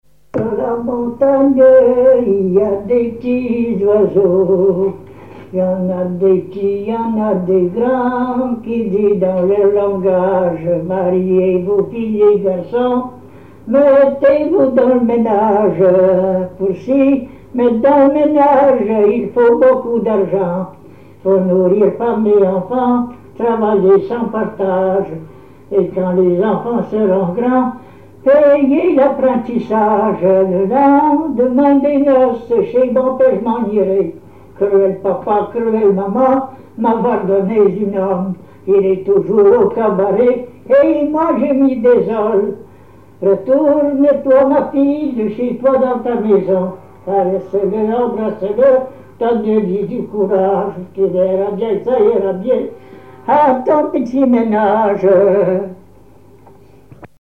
Mémoires et Patrimoines vivants - RaddO est une base de données d'archives iconographiques et sonores.
instrumentaux à l'accordéon diatonique
Pièce musicale inédite